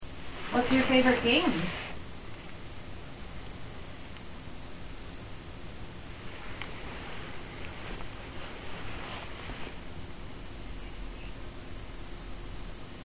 Right Bedroom – 9:35 pm
There is a noise in response to the question “What is your favorite game”